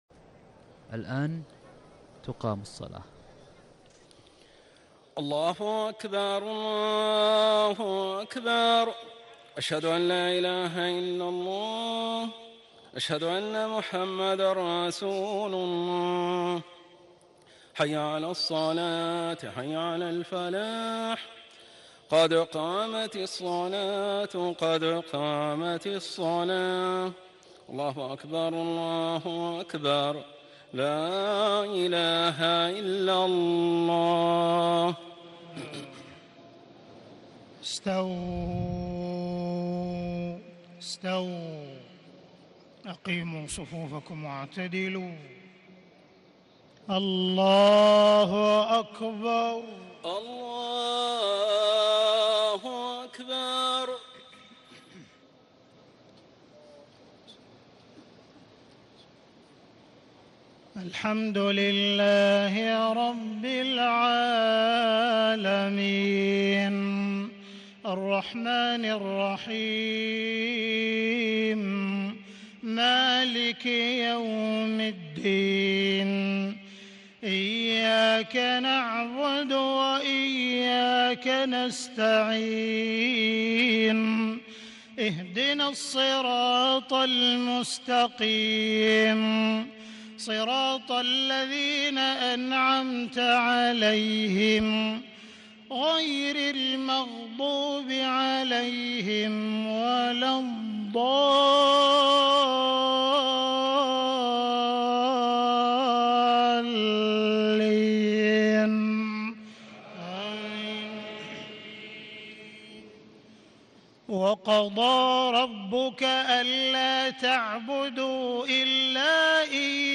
صلاة المغرب 24 رمضان 1437هـ من سورة الإسراء 23-30 > 1437 🕋 > الفروض - تلاوات الحرمين